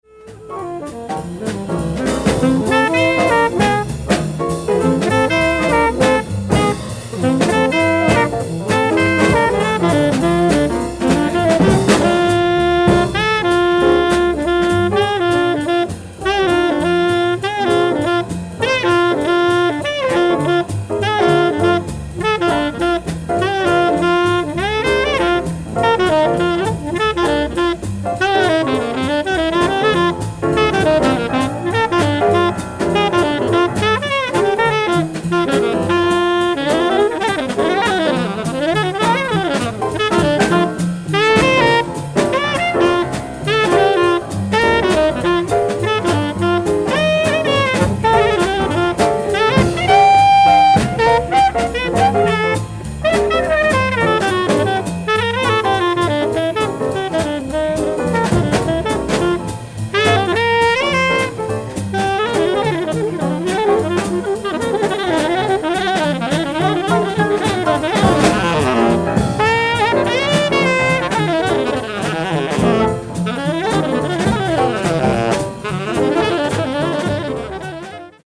ライブ・アット・デュッセルドルフ、ドイツ 03/28/1960
※試聴用に実際より音質を落としています。